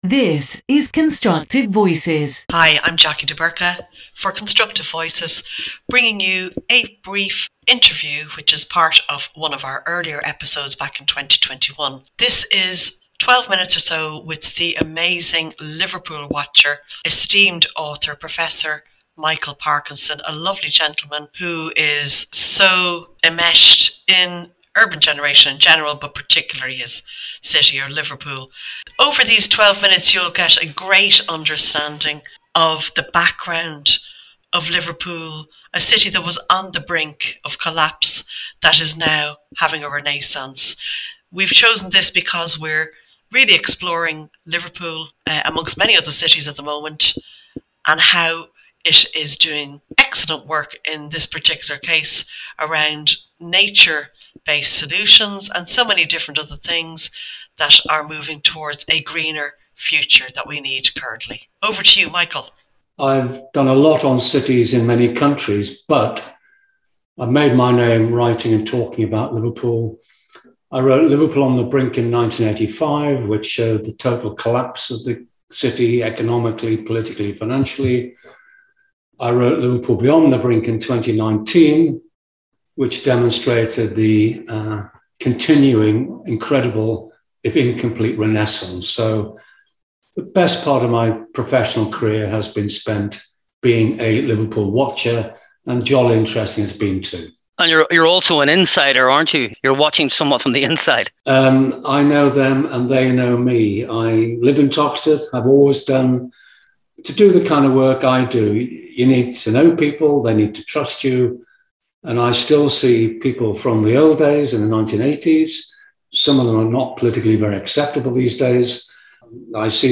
This bonus episode features a 12-minute excerpt from a 2021 interview